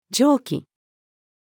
蒸気-female.mp3